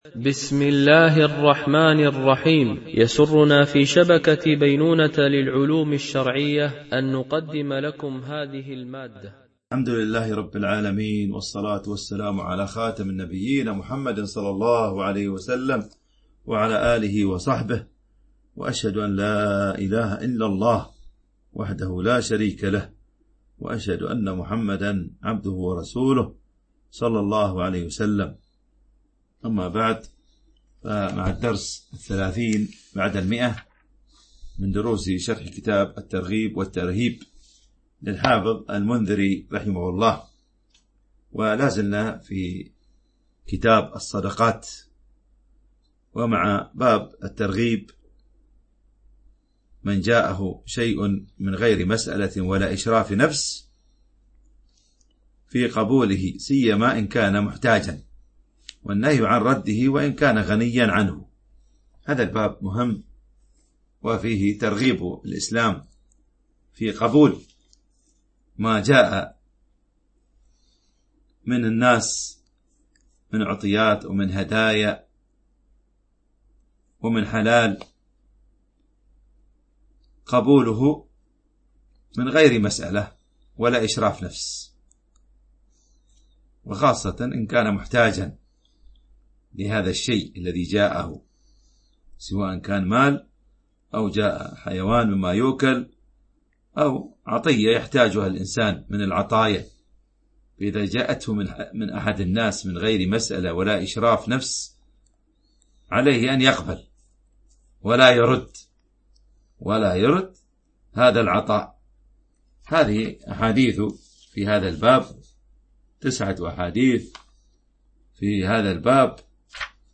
شرح كتاب الترغيب والترهيب - الدرس 130 ( كتاب الصدقات - من جاءه شيء من غير مسألة ولا إشراف نفس... - الحديث 1653 - 1661 )
- الحديث 1653 - 1661 ) الألبوم: شبكة بينونة للعلوم الشرعية التتبع: 130 المدة: 30:27 دقائق (7.01 م.بايت) التنسيق: MP3 Mono 22kHz 32Kbps (CBR)